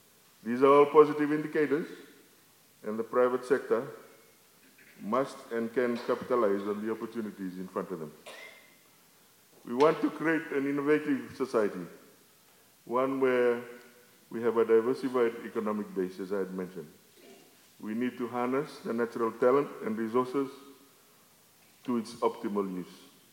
The two ministers were speaking at a Fiji Employers and Commerce Federation breakfast event.